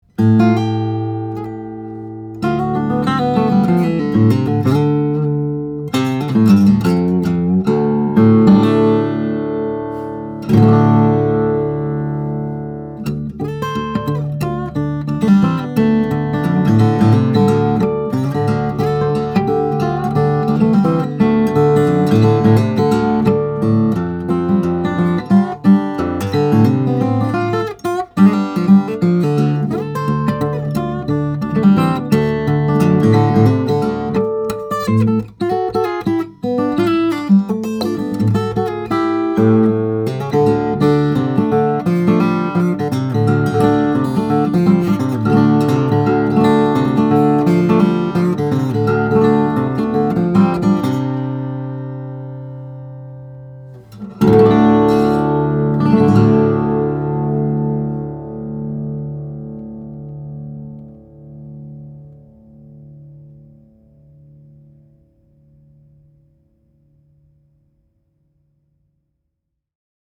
Power and poise, in a petite package! This 2016 Greven is the real deal, folks, a powerhouse that loves an aggressive attack but has the responsiveness and dynamic range to bloom under a light touch too.